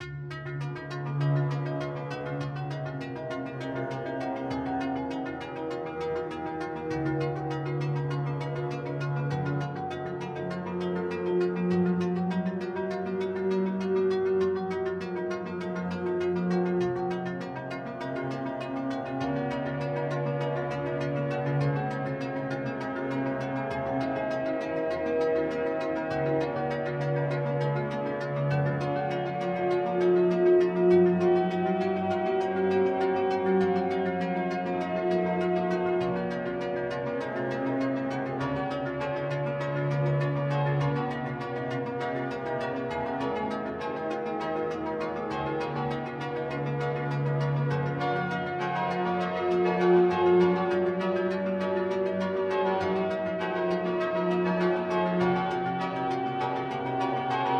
Part of the same project, but a little dark and heavy themed ones.Also a part of summer Art challange - Platformer